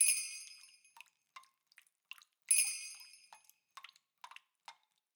sfx_鬼差出场.wav